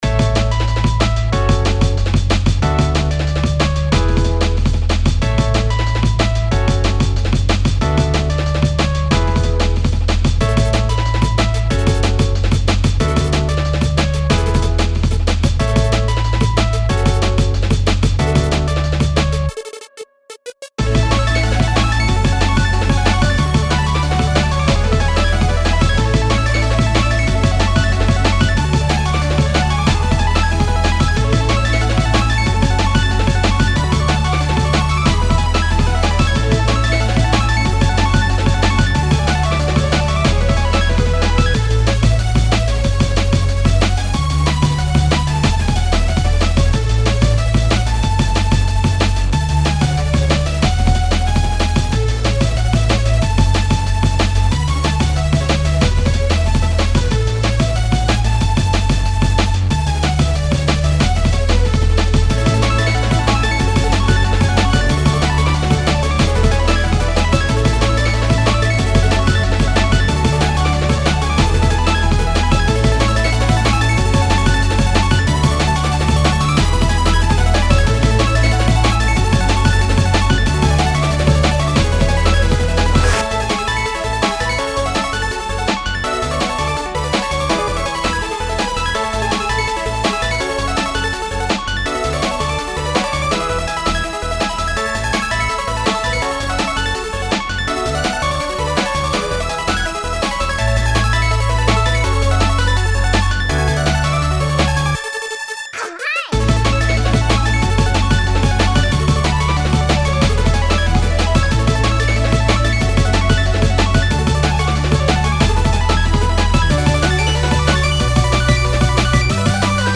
So this track was fun because it was my first time slicing up drums to use as samples!
Plus, I used a pair of headphones made by a friend of mine for the mixing and mastering!
Music / Techno